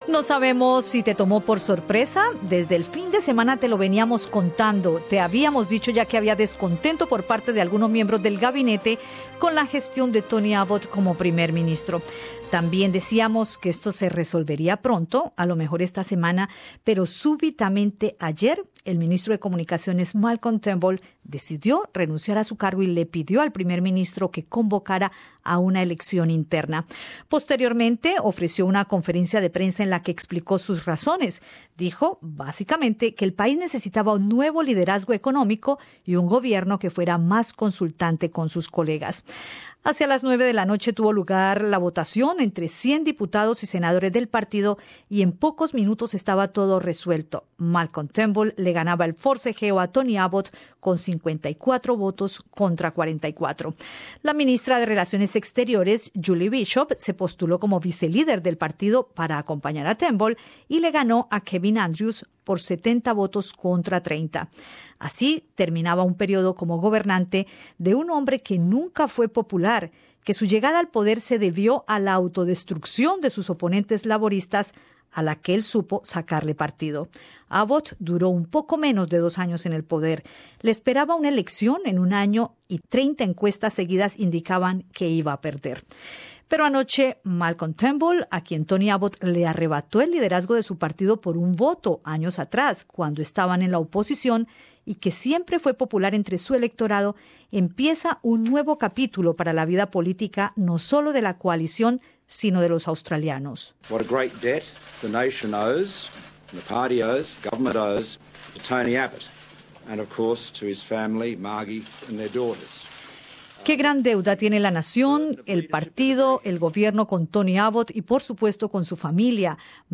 Escucha aquí dos puntos de vista distintos sobre los acontecimientos que derivaron en la caída de un mandatario y el surgimiento de otro.